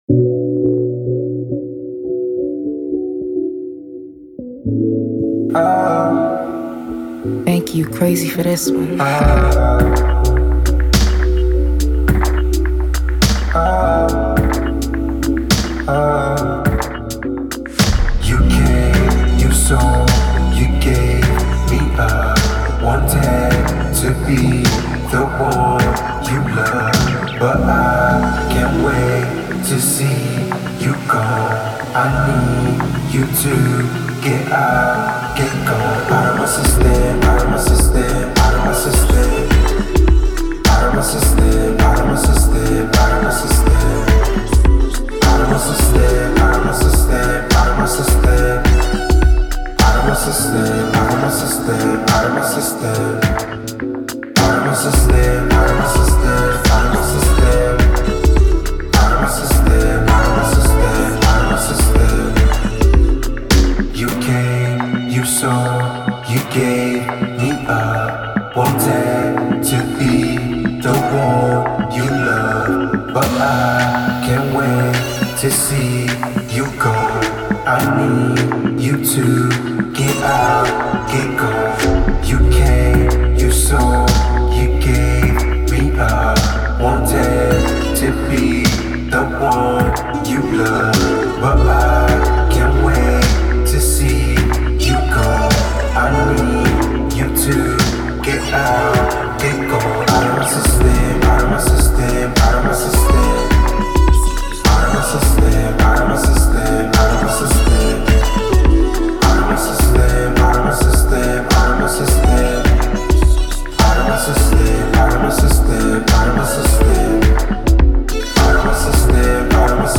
Afro-futurist
is a fine blend of afrocentric and futuristic sounds